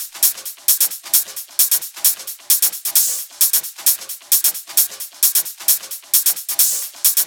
VFH3 132BPM Elemental Kit 8.wav